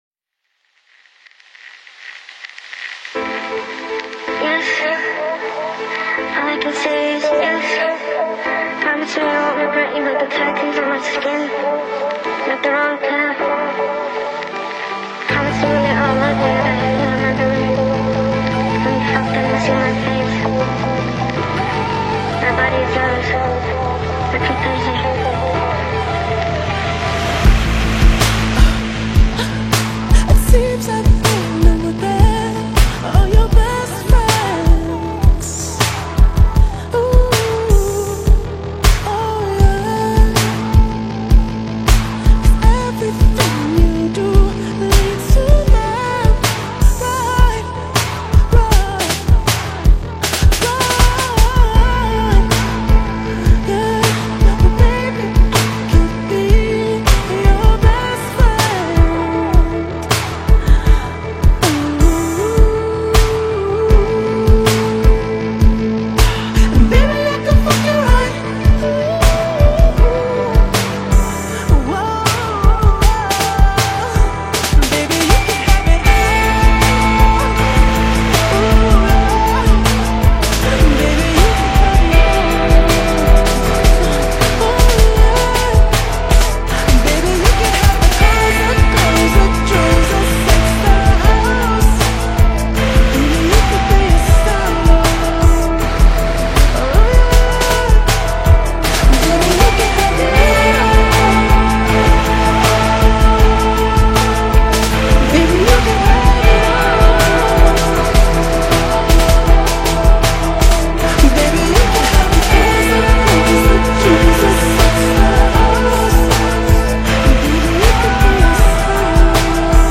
Alternative R&B, Dark R&B